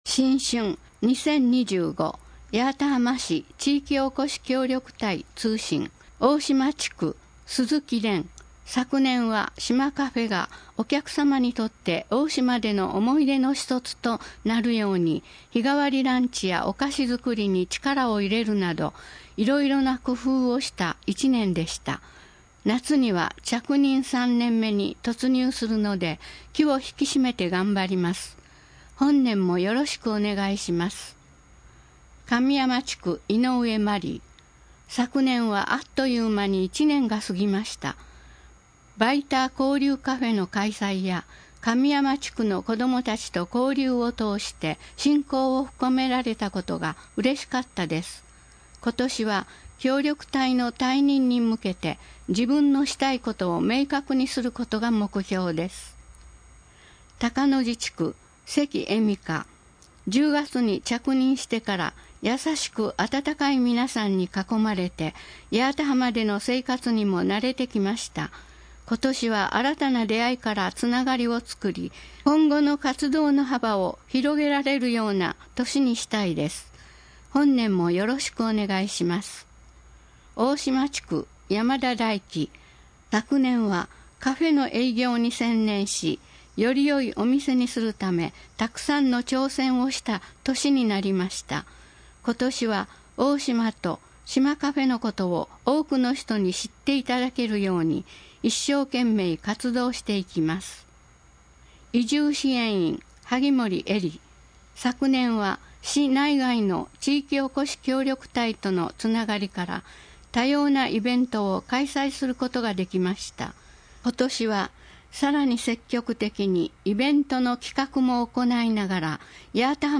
なお「声の広報」は、朗読ボランティアどんぐりの協力によって作成しています。